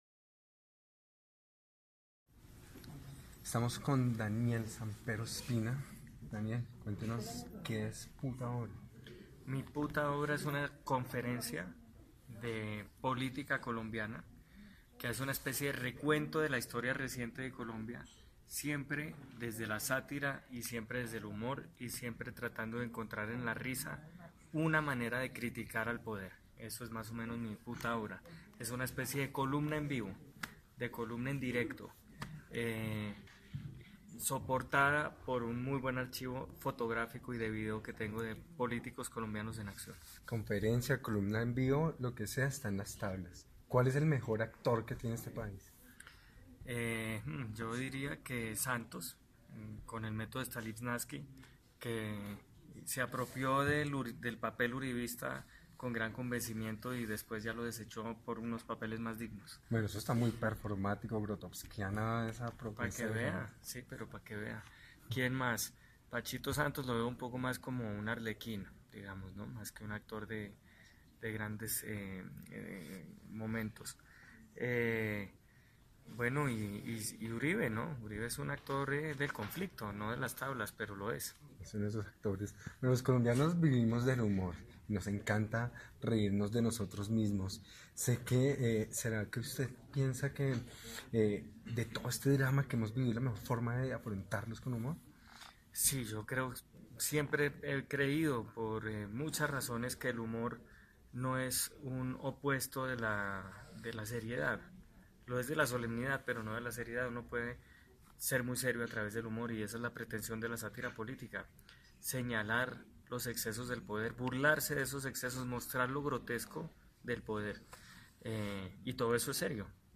Entrevista 2017. Informe radial: "Daniel Samper 2017"
Entrevista con Daniel Samper Ospina sobre su obra titulada "Mi Puta Obra", una conferencia de sátira política que utiliza el humor para analizar la historia reciente de Colombia y criticar a la clase dirigente. Habla sobre los políticos como actores, el papel del humor frente a la solemnidad, situaciones insólitas en el país, y cómo el Teatro Nacional apoyó este proyecto.